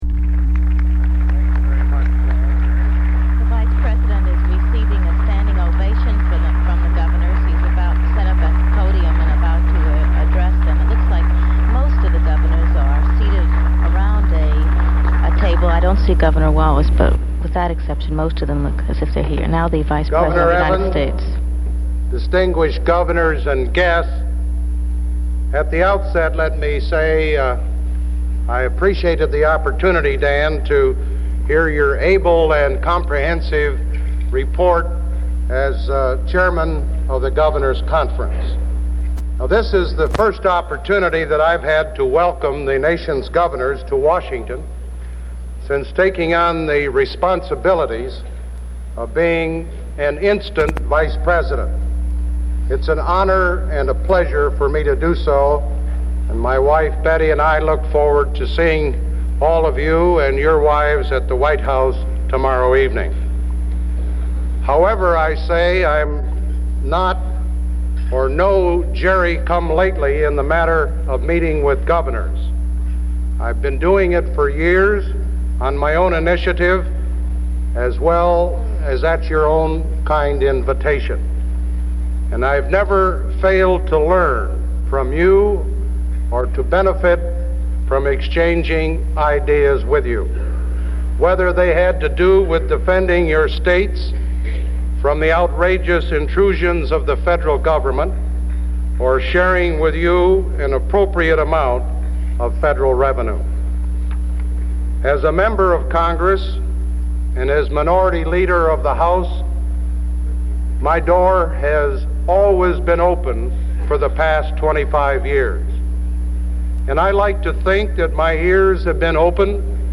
President Ford speaks about the right to privacy